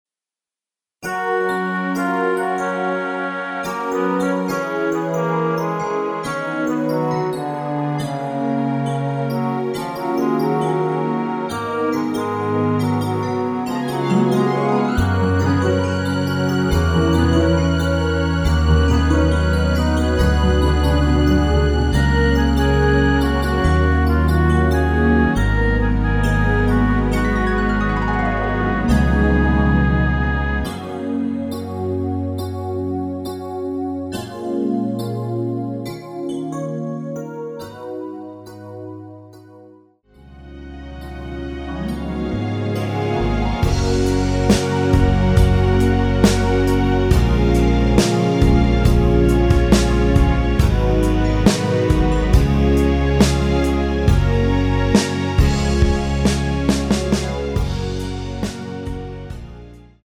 F#
◈ 곡명 옆 (-1)은 반음 내림, (+1)은 반음 올림 입니다.
앞부분30초, 뒷부분30초씩 편집해서 올려 드리고 있습니다.
중간에 음이 끈어지고 다시 나오는 이유는